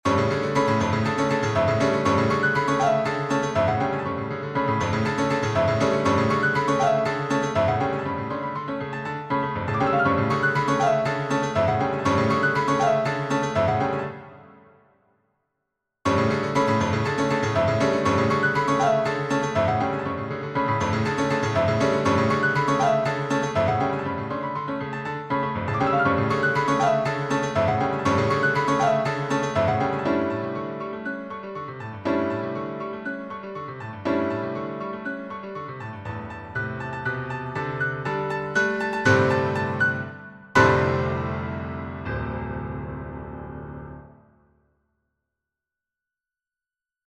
He aquí mi nueva pieza, es mi primer intento de componer una pieza politonal, yo uso mi método que es que creo un acorde todo expandido encima del soprano y un acorde contrario en el bajo, usando 2da, 3ra, 4ta y hasta la octava, y a partir de este bloque construyo la canción, ahorita estoy practicando con la forma básica ABABCBB y luego ABABCBBDE+outro, aquí les dejo el audio y el video.
Mis orejas (ojeras a veces...) tiran mucho para la melodía en la voz superior (en LaM aparéntemente) y un acompañamiento de Do#Re a modo de ostinato aderezado con semicorcheas que se repiten según cómo se presentan en los modelos A y B, sobre todo.
E más la Coda/Outro se me hace fresco